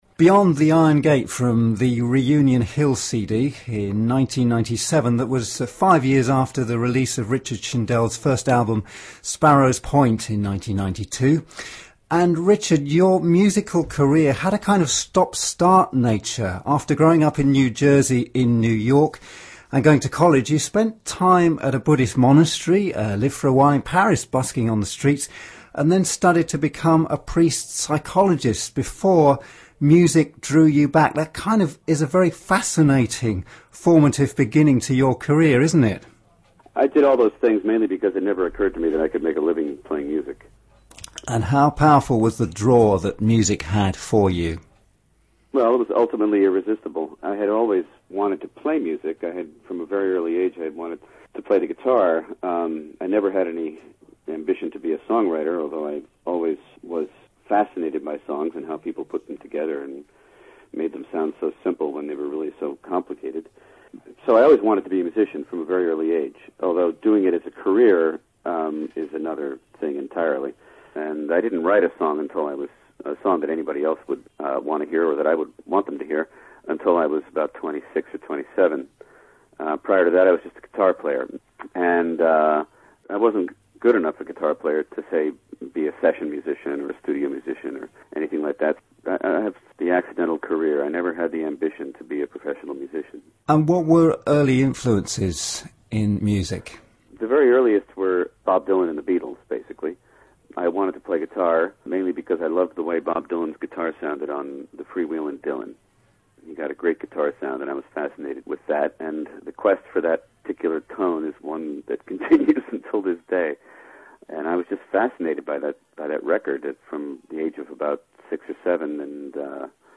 Green Radio audio site featuring environmental issues, human rights, peace work and life affirming interviews.